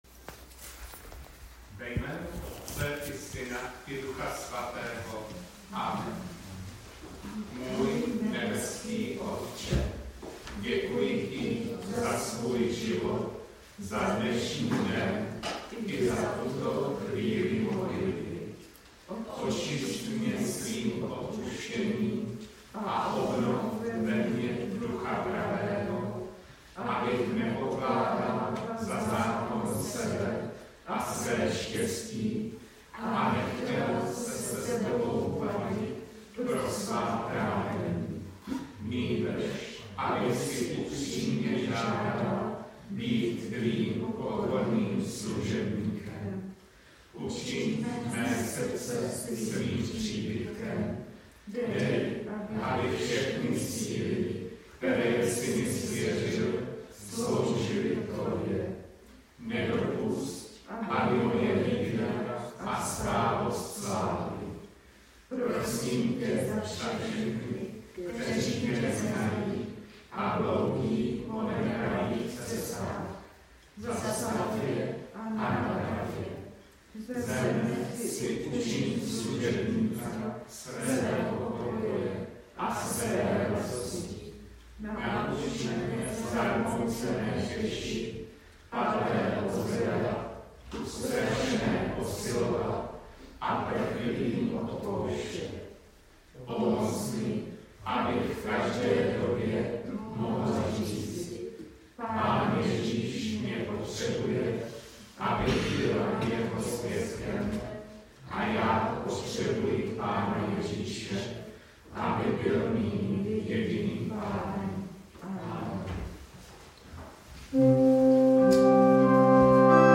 Přehled audio a videozáznamů bohoslužeb Církve československé husitské z 2. postní neděle roku 2021. Texty: Genesis 17, 1-7.15-16 nebo Genesis 22, 1-14a; Římanům 4, 13-25 nebo Římanům 8, 31-39, Marek 8, 31-38.